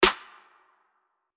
Metro Snare [Star].wav